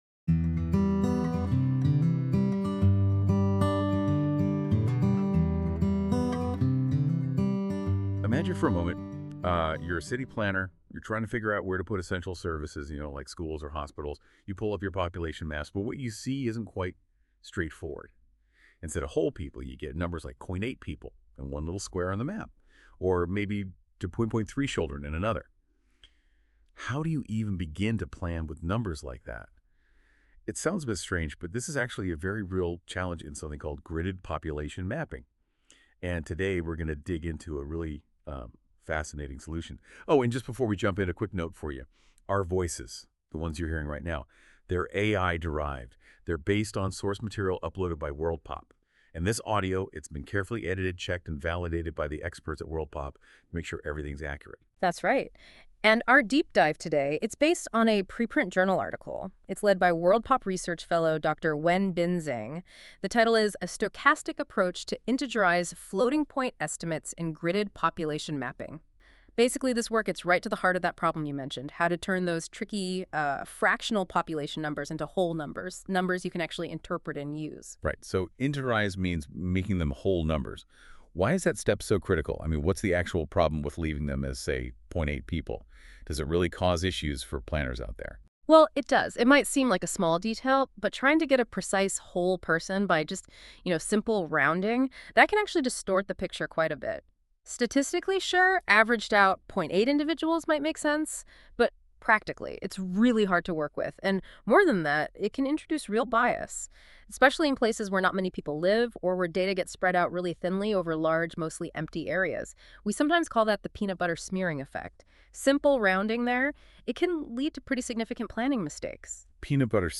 This feature uses AI to create a podcast-like audio conversation between two AI-derived hosts that summarise key points of a document - in this case the Stochastic Approach to Integerize Floating-point Estimates article in International Journal of Geographical Information Science.
Music: My Guitar, Lowtone Music, Free Music Archive (CC BY-NC-ND)